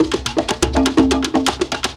Percussion 24.wav